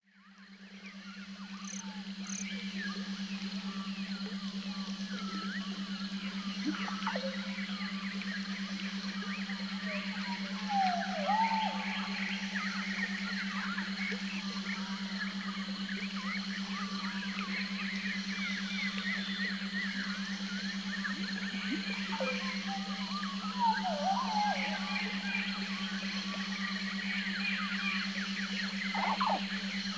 Beautiful nature scenes for relaxing.